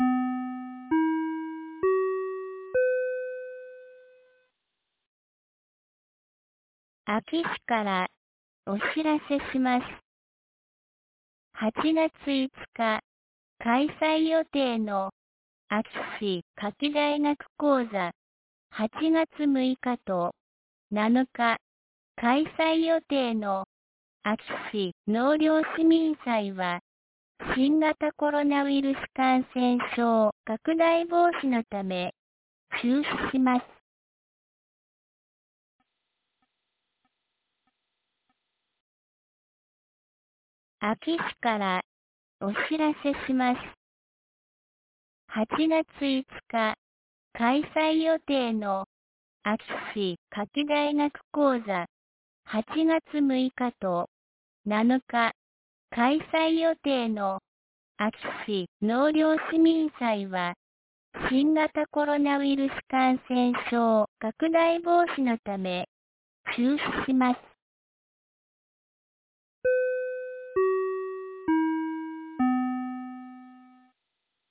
2022年08月04日 12時06分に、安芸市より全地区へ放送がありました。
放送音声